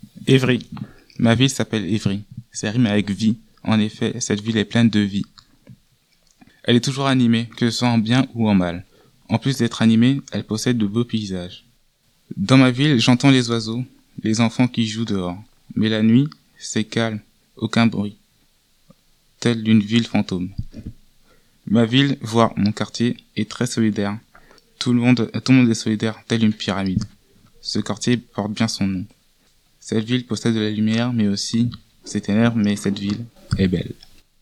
Calme et animée, telle est la ville d'Evry, selon cet habitant des Pyramides.